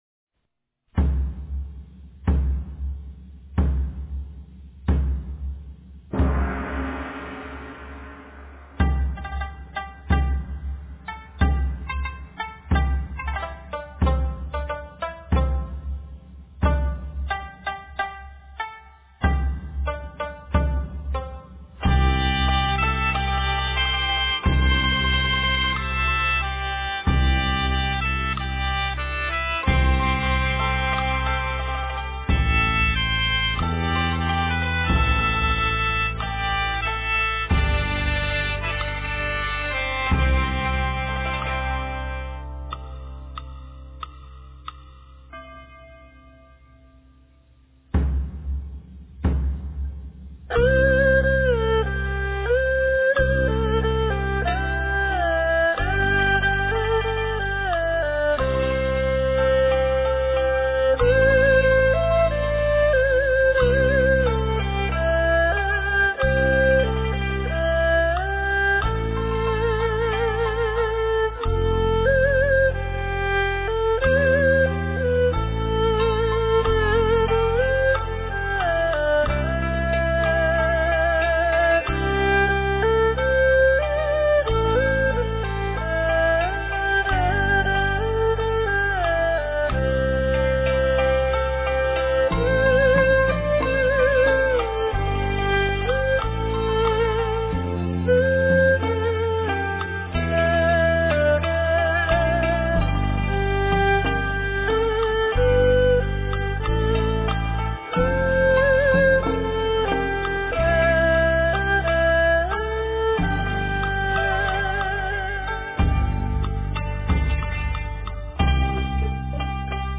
佛音 冥想 佛教音乐 返回列表 上一篇： 诗经-月出(丝弦版